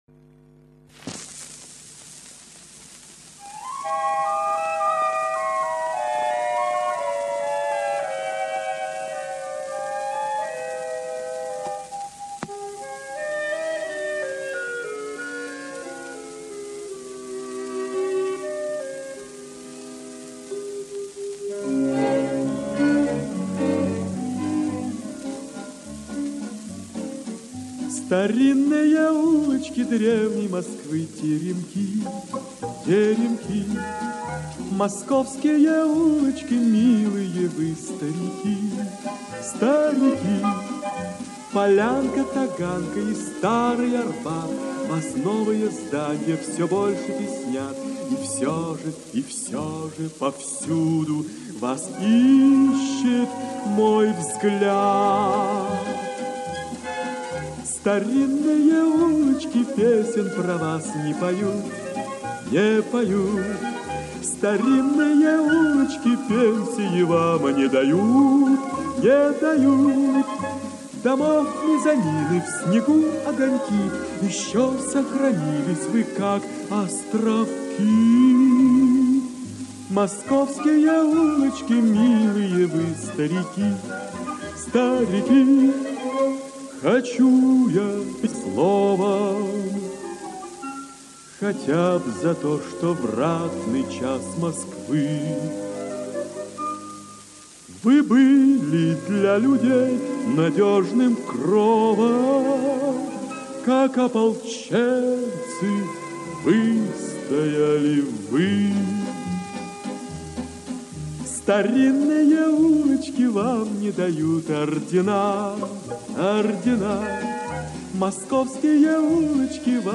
Инстр. ансамбль